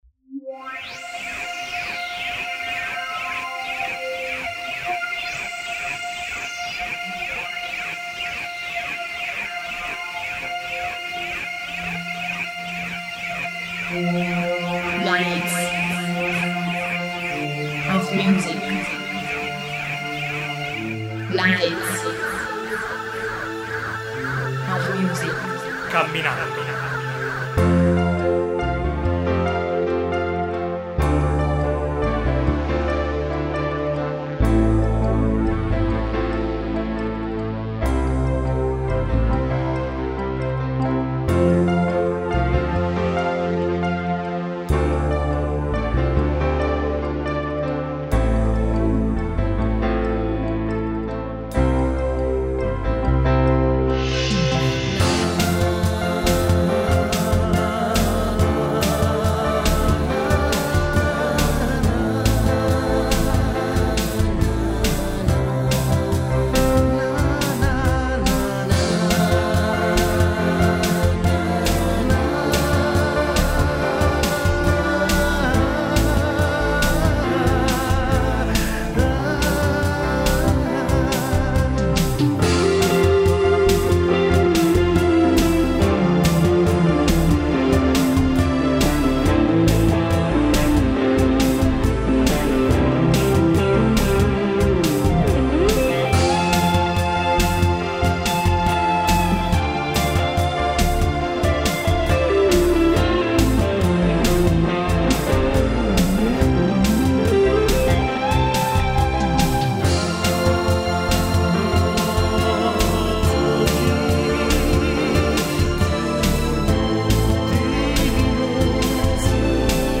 chitarra elettrica